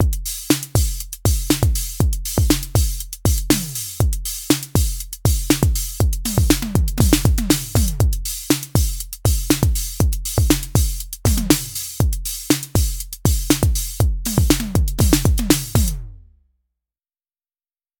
So when our open hat is ringing out the closed hat will stop it from playing.
The new high hat sounds in the mix